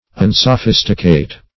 Search Result for " unsophisticate" : The Collaborative International Dictionary of English v.0.48: Unsophisticate \Un`so*phis"ti*cate\, Unsophisticated \Un`so*phis"ti*ca`ted\, a. Not sophisticated; pure; innocent; genuine.